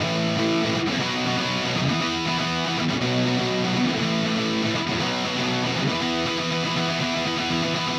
Power Pop Punk Guitar 01a.wav